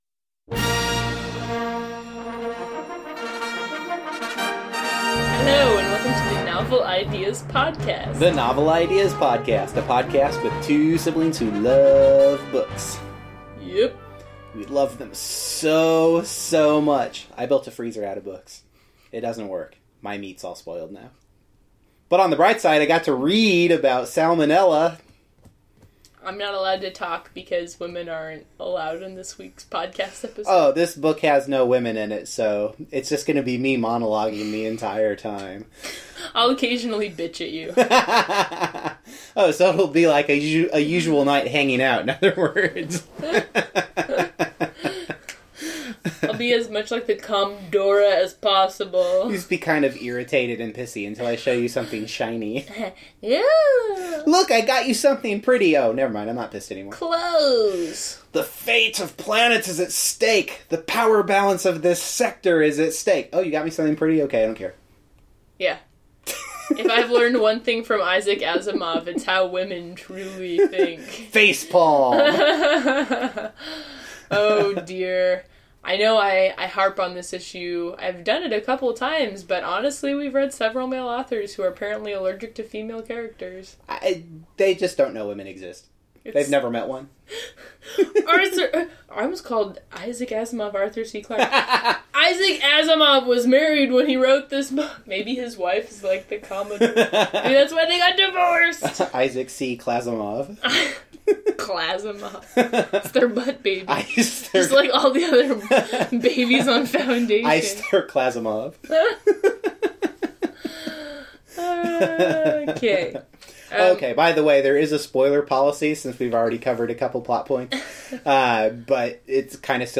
This week’s episode features Foundation by Isaac Asimov. This is one of the gigglier episodes we’ve recorded thus far and we hope that you’ll enjoy it as much as we do.